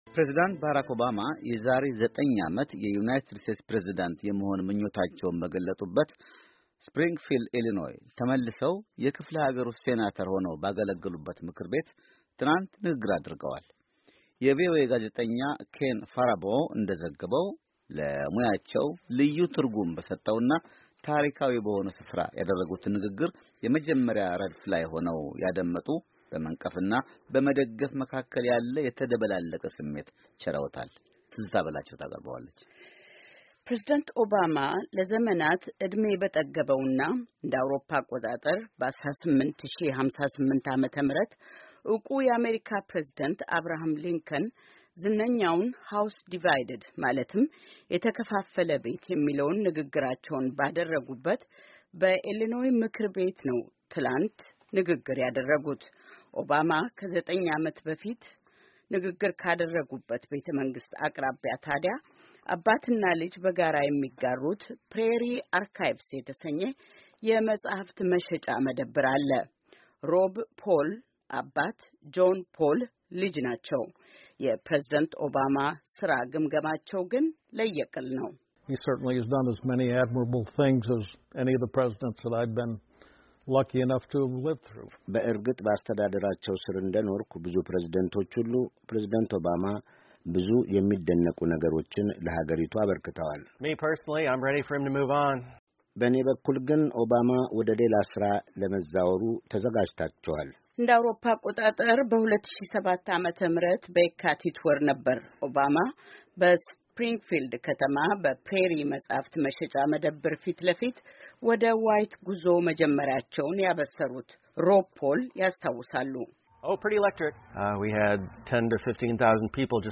U.S. President addresses the Illinois General Assembly during a visit to , , Feb.10, 2016.